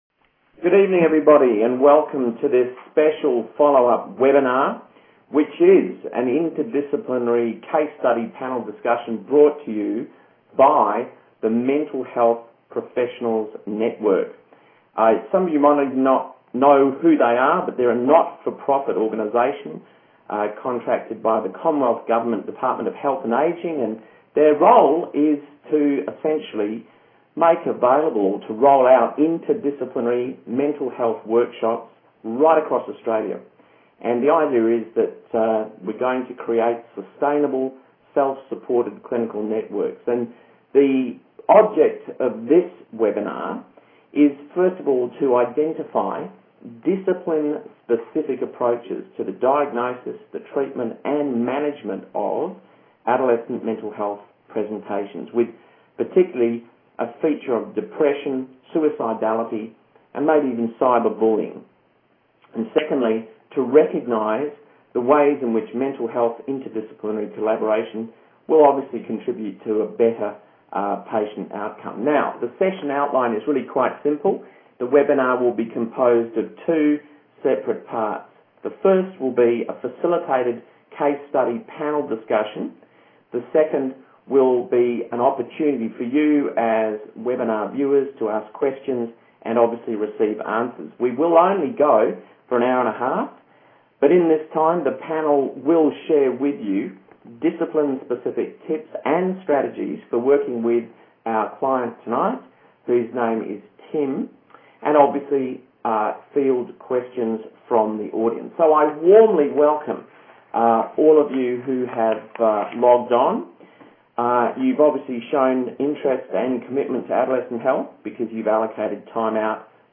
This webinar features an interdisciplinary panel discussion covering the key principles of intervention and different discipline roles in providing integrated primary mental health care services to Suzie (a fictional person) who's story is explored via a case study.